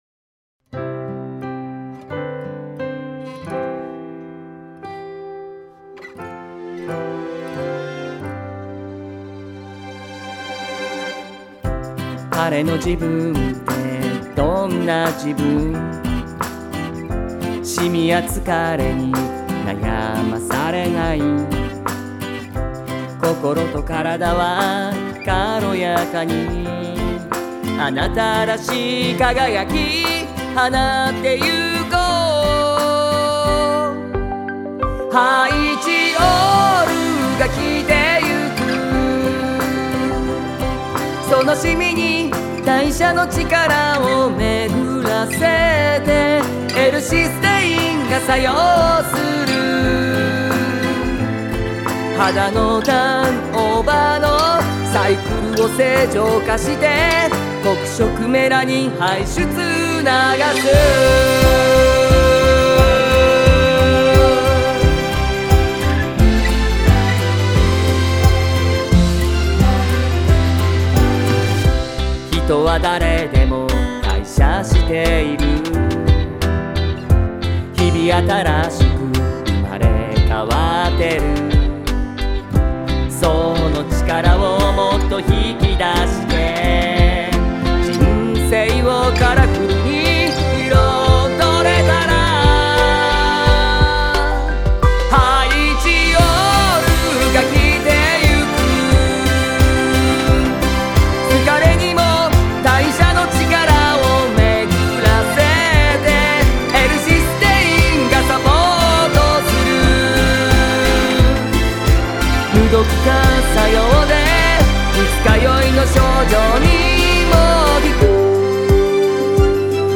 エモーショナルなメロディーと機能的な歌詞の組み合わせで、個性溢れる楽曲が産まれました。
SONG ROCK / POPS